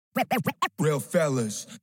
Battle Rap Vocals
BRV_Real_fellas_Scratch